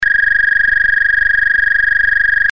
ZUMBADOR CON 8 SONIDOS
Zumbador Electrónico Empotrable para cuadro Ø 22,5MM
dB 86-100